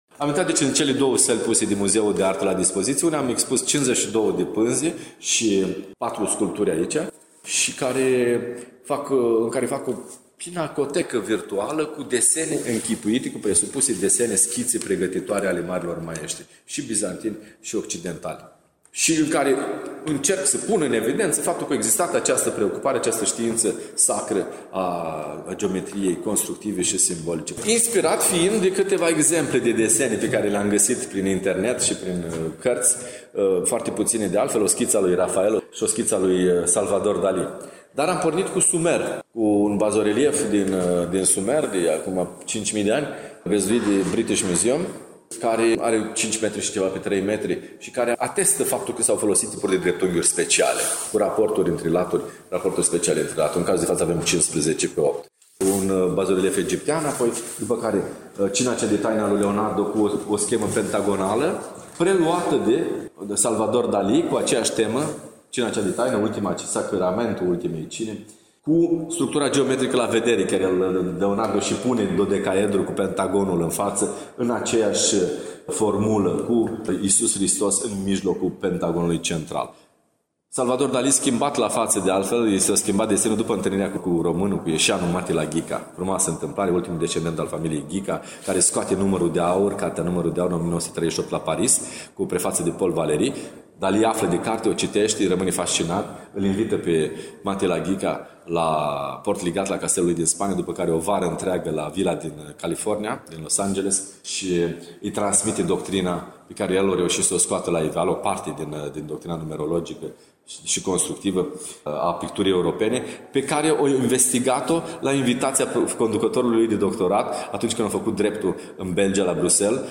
Dar să-l ascultăm pe invitatul emisiunii nostre